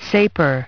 Транскрипция и произношение слова "sapor" в британском и американском вариантах.